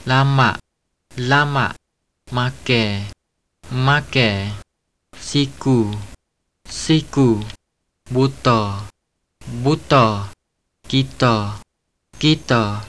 7.7: Pattani-malajisk [ˈlamaʔ ˈl:amaʔ ˈmakɛ ˈm:akɛ ˈsiku ˈs:iku ˈbutɔ ˈb:utɔ ˈkitɔ ˈk:itɔ]